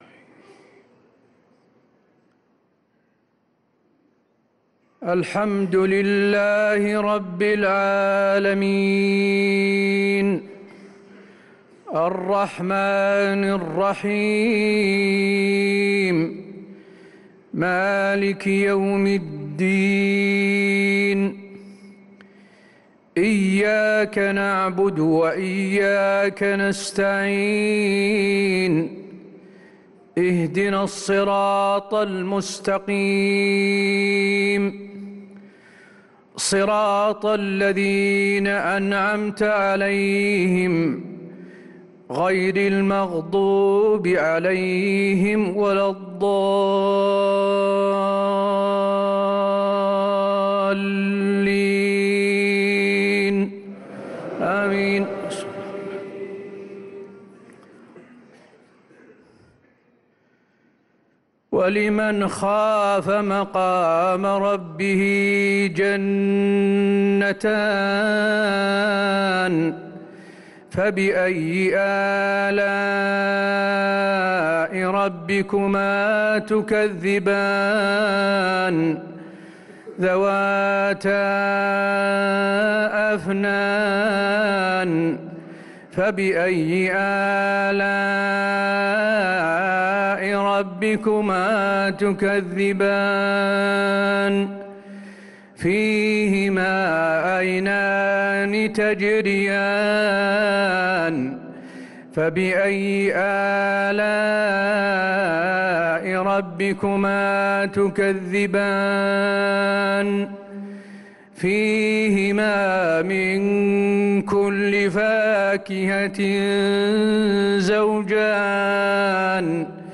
صلاة العشاء للقارئ حسين آل الشيخ 22 رمضان 1445 هـ
تِلَاوَات الْحَرَمَيْن .